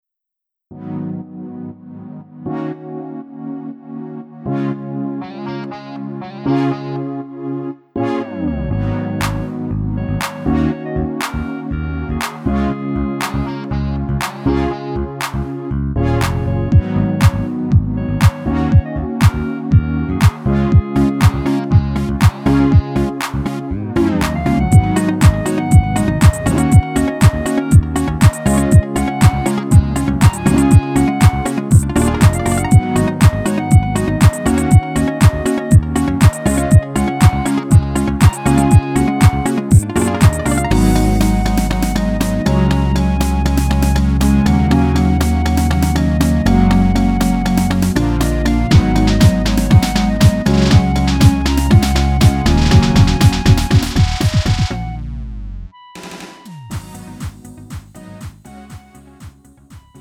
음정 -1키 장르 가요